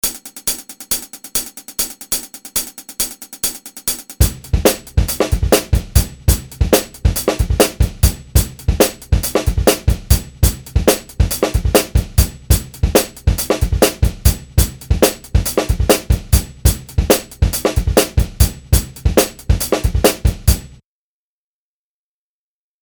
Drums: